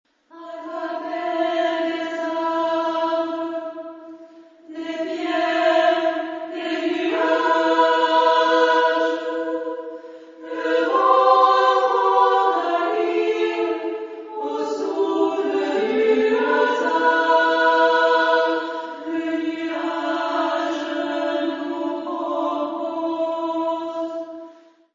Genre-Style-Forme : Profane ; Poème ; contemporain
Type de choeur : SSAA  (4 voix égales OU égales de femmes )
Tonalité : mi mode de mi